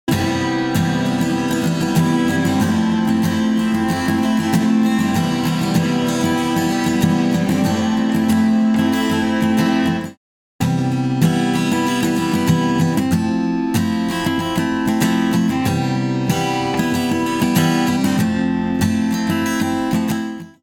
UltraReverb | Electric Guitar | Preset: ML Delay Vox Hall
Guitar-ML-Delay-Vox-Hall.mp3